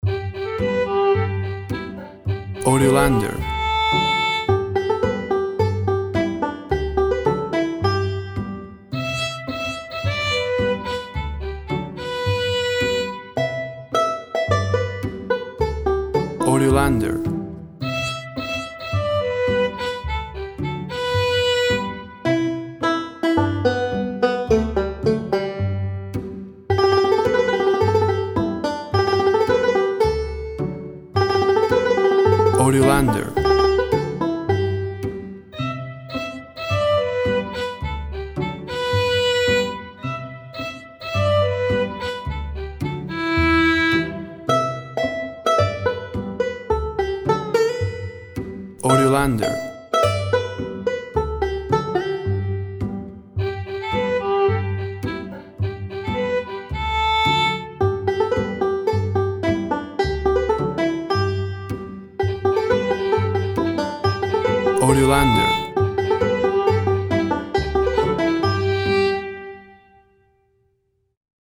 Lively and light-hearted folk instrumental.
Tempo (BPM) 136